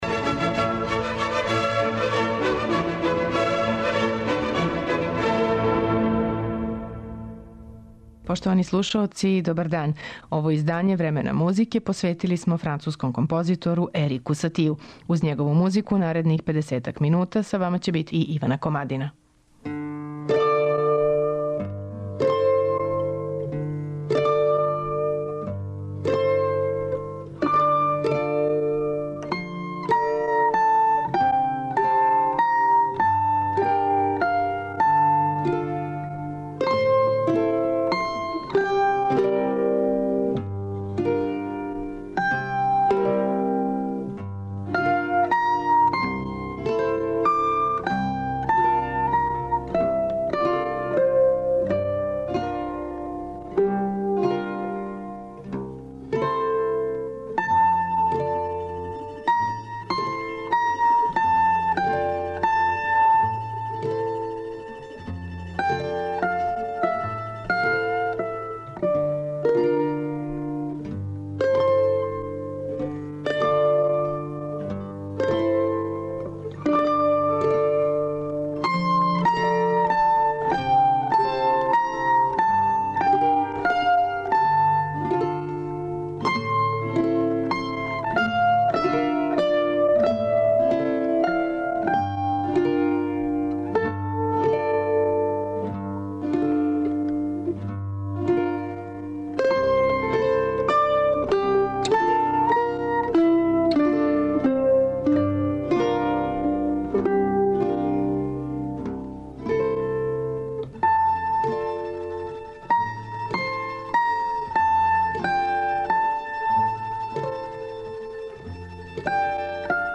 за квартет мандолина.